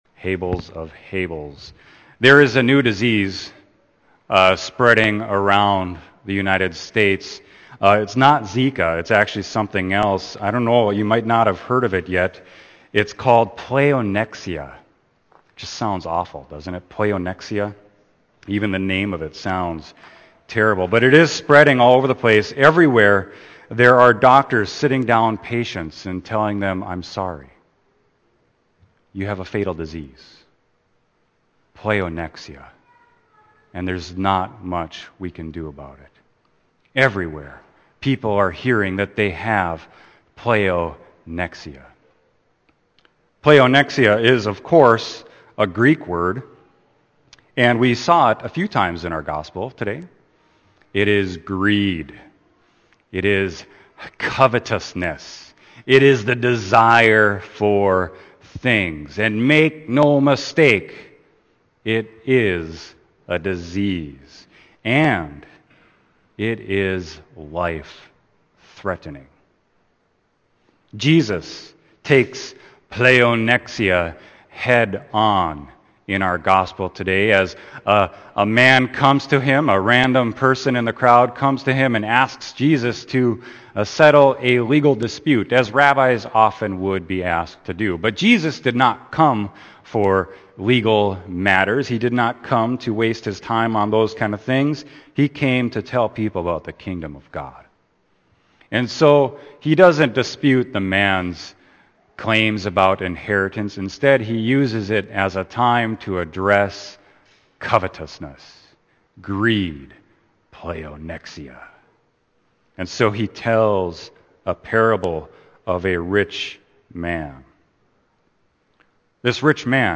Sermon: Luke 12.13-21